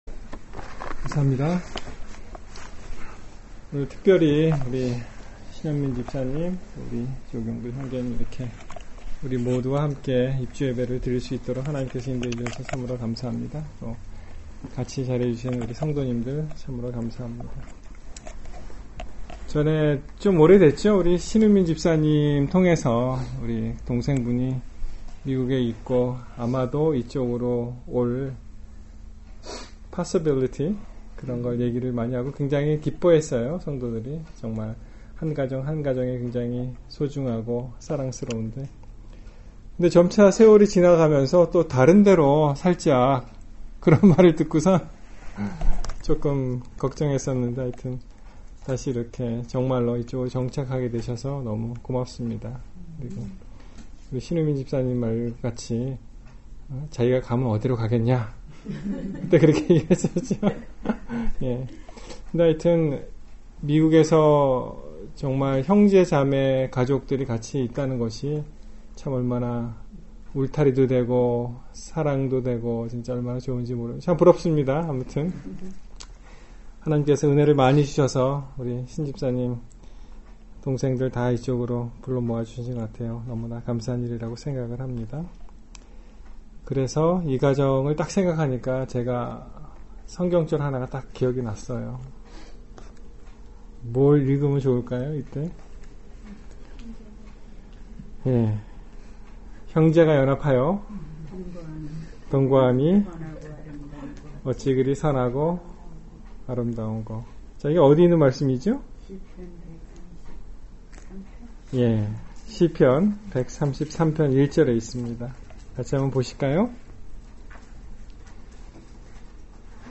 New Home worship